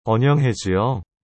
Annyeonghaseyo-2.mp3